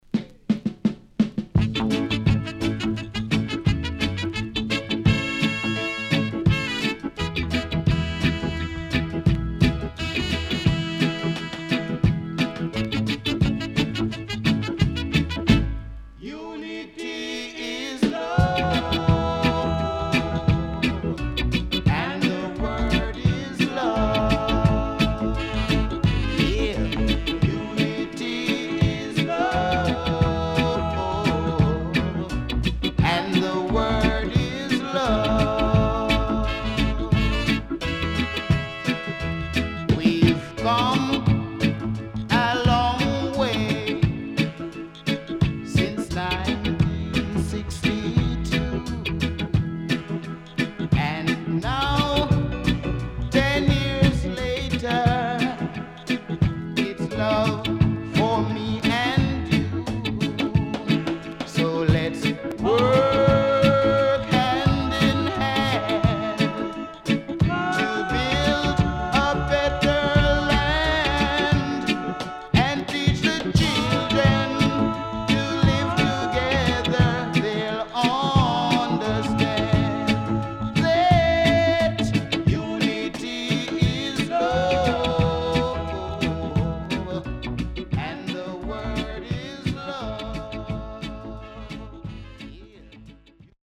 Good Vocal & Deejay.Miss Credit